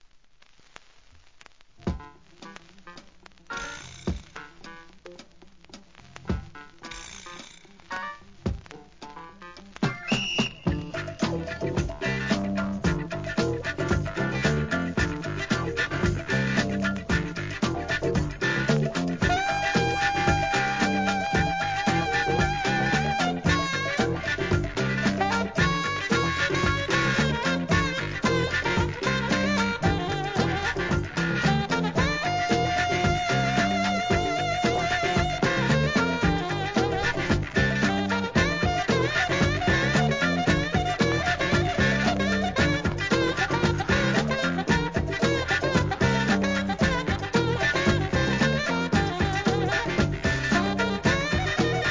SOUL/FUNK/etc... 店舗 数量 カートに入れる お気に入りに追加 ファンキーなホーンINST.